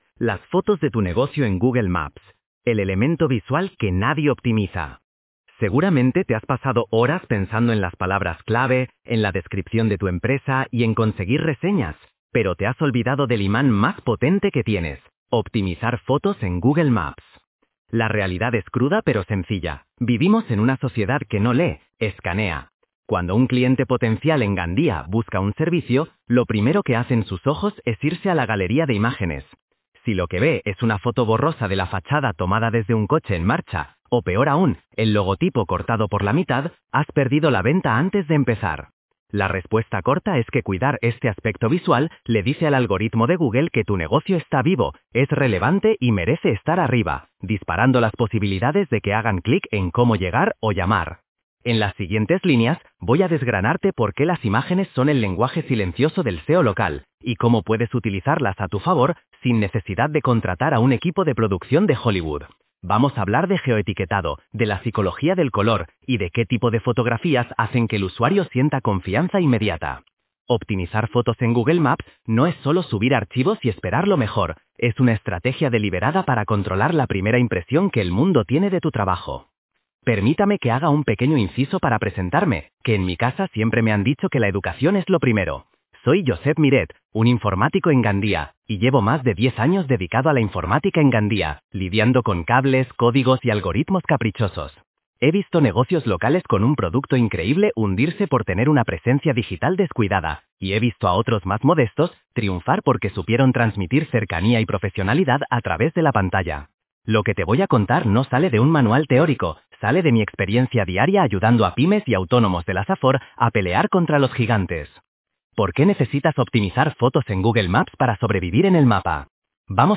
Dale al play para escuchar el artículo Optimizar fotos en Google Maps